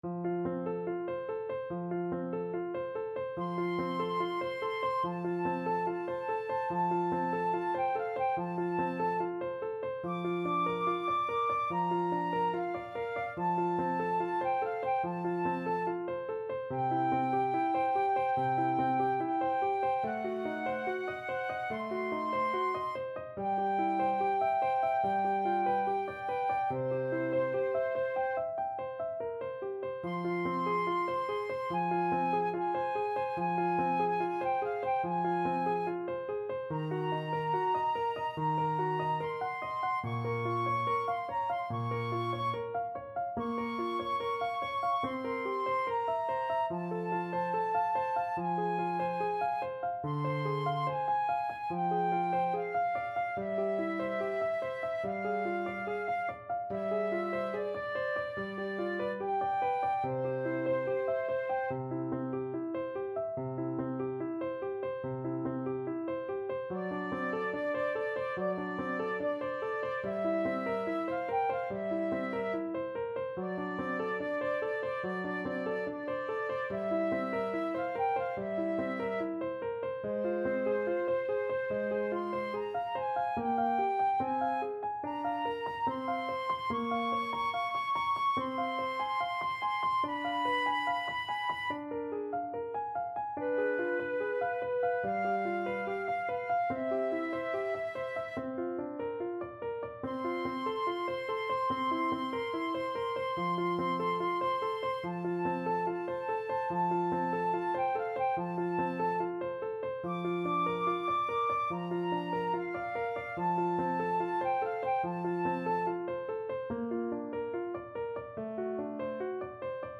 Andante =72
Flute Duet  (View more Intermediate Flute Duet Music)
Classical (View more Classical Flute Duet Music)